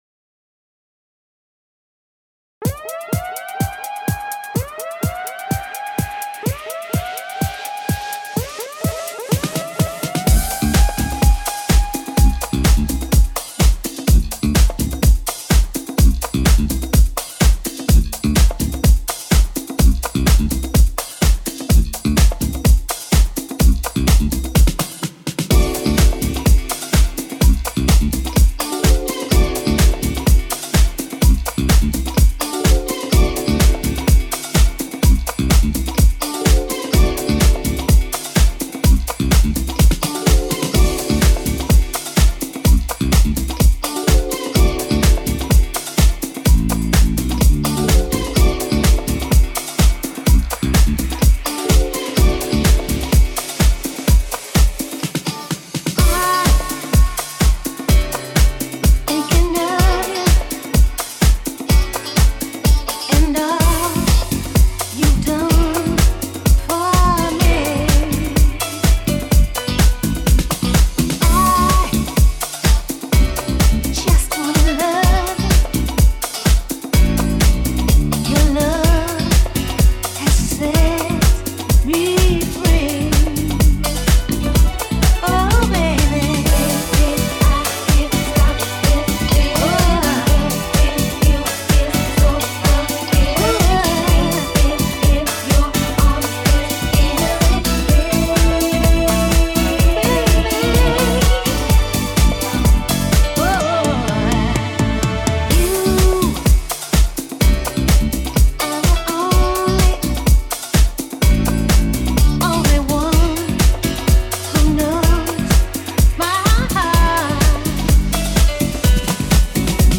Expect, Disco, Soulful, Latin, House and more!